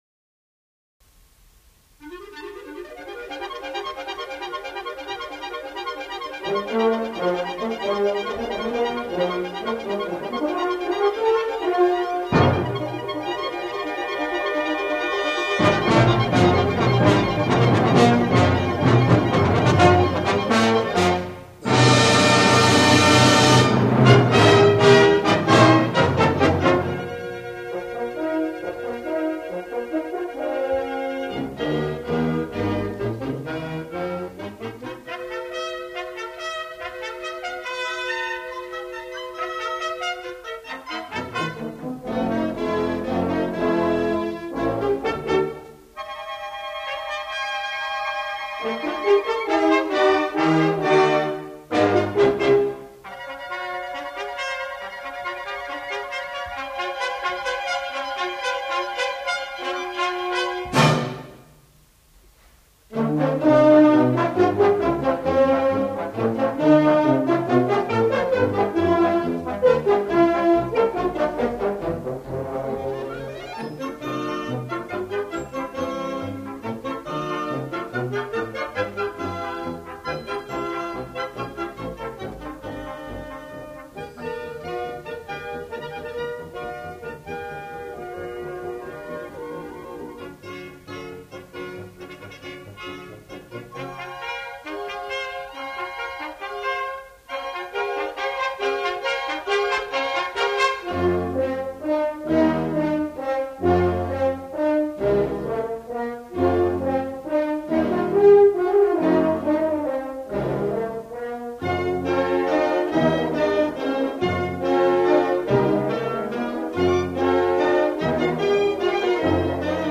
１９７０年代の高等部吹奏楽団の演奏会記録です
玉川学園高等部吹奏楽団 第８回定期演奏会
1975年3月21日(日) / 文京公会堂